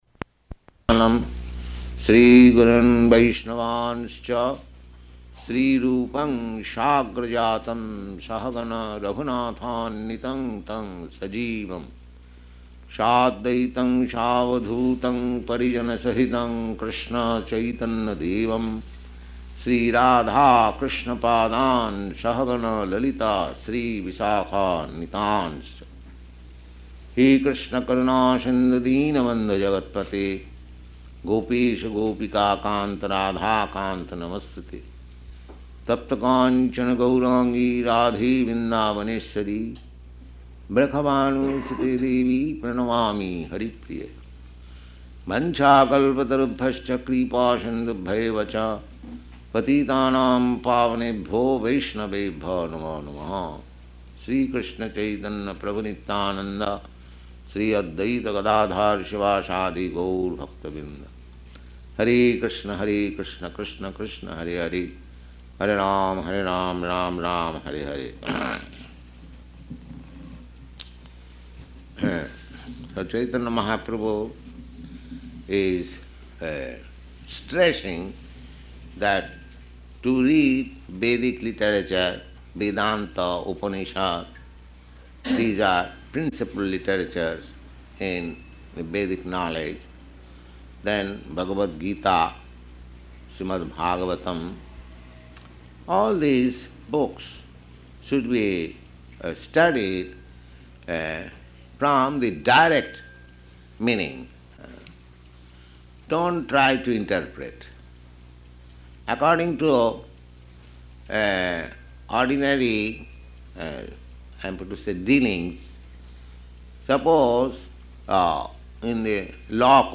Location: San Francisco
Prabhupāda: [chants maṅgalācaraṇa prayers]